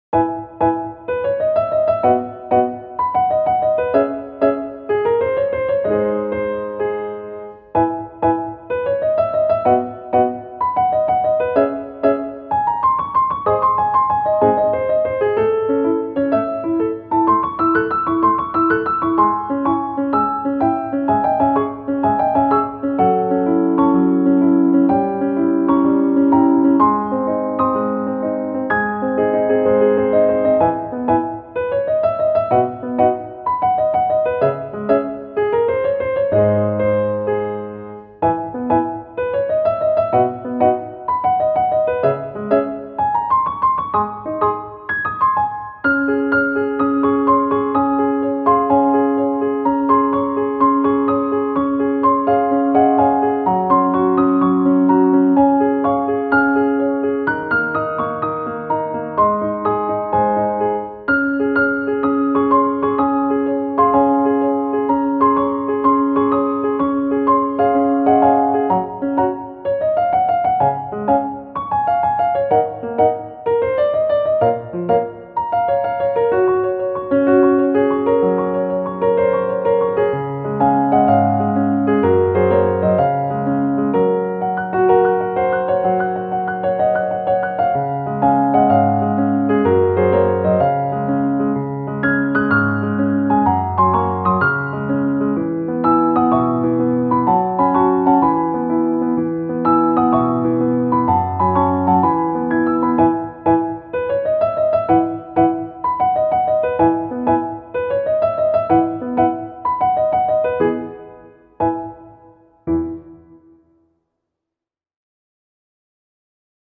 かわいい 軽やか メルヘン